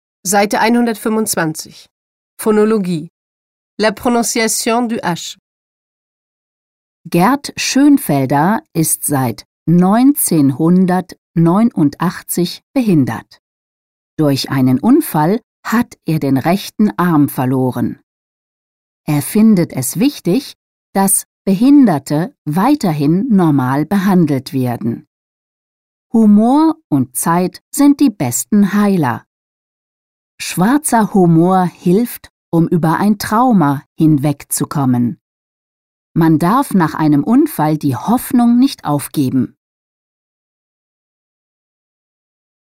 1. Aussprache von "-ä-" [ɛ], "-ö-" [ø] und "-ü-" [y]                           (Voyelles infléchies)